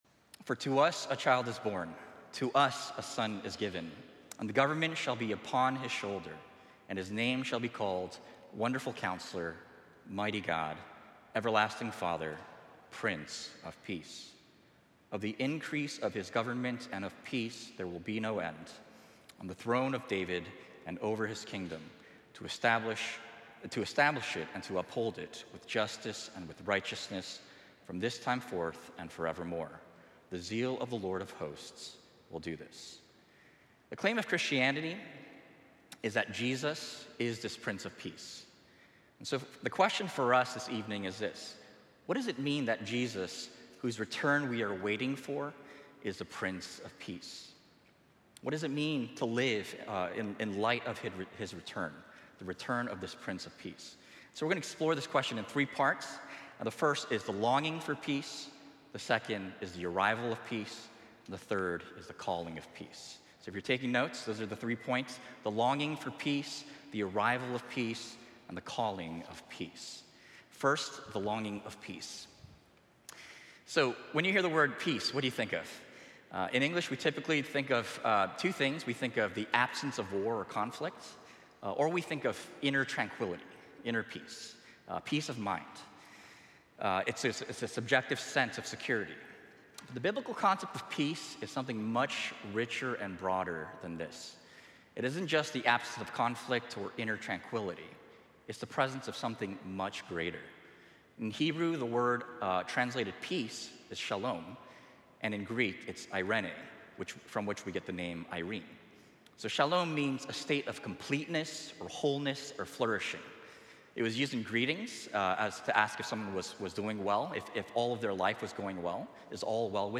1 Sermon: When Provision is the Test - Exodus: The Story of God